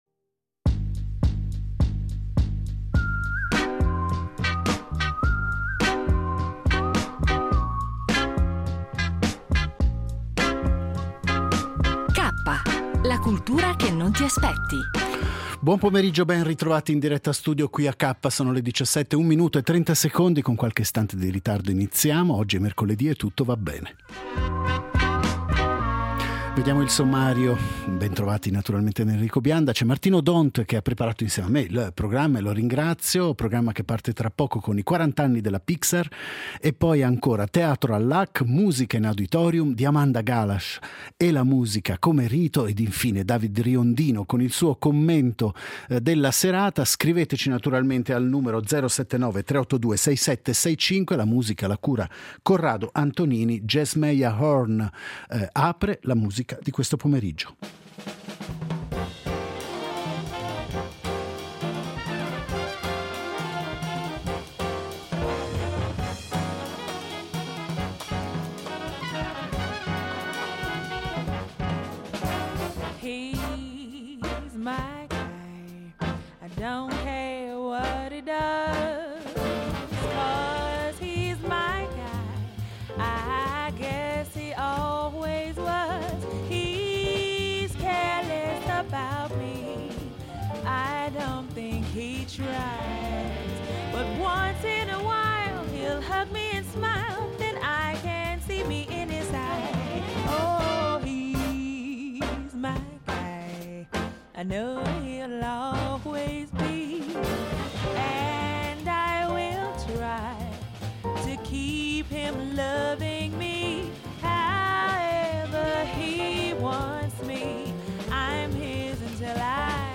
con un’intervista al giornalista